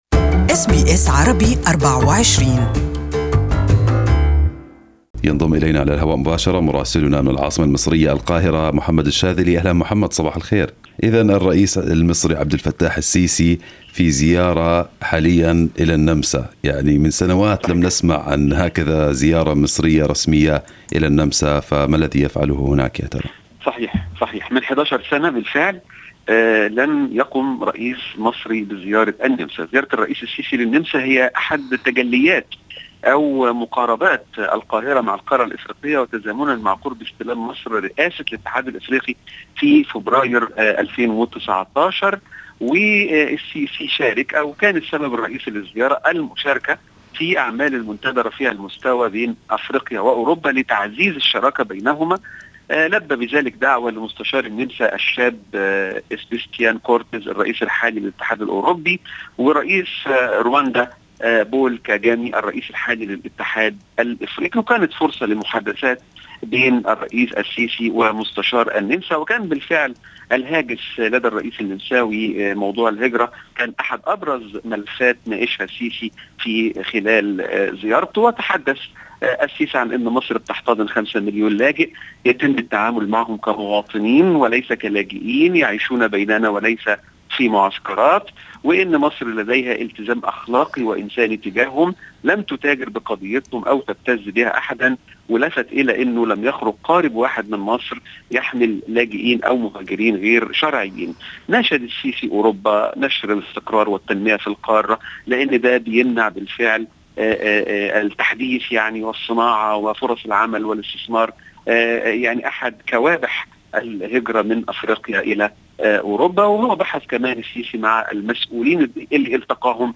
Our correspondent in Egypt has the details